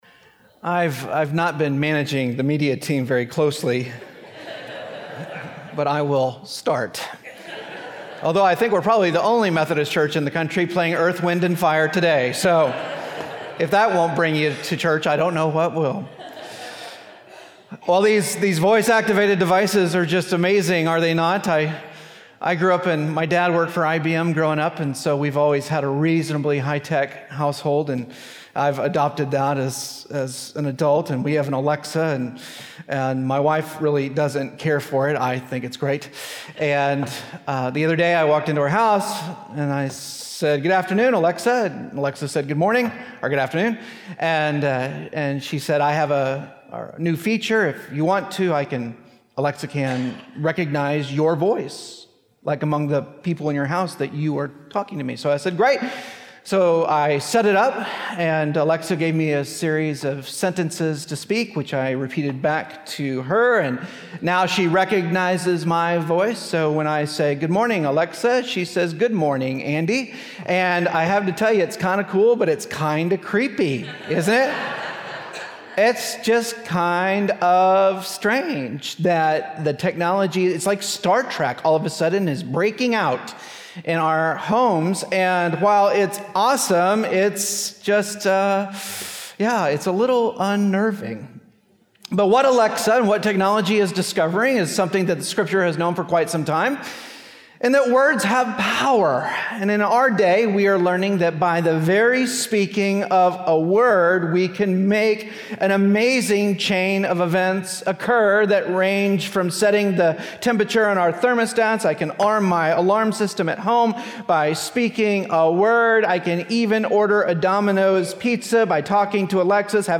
Passage: 1 Kings 19:9-13 Service Type: Traditional